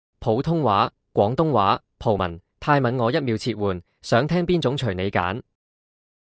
TTS语音合成
这一代 TTS 不再是“机器发声”，而是可以真正传递情感和温度的 AI 声音，在自然度、韵律、口气、情绪、语气词表达等方面全面突破，让听者几乎无法分辨“人声”与“机器声”。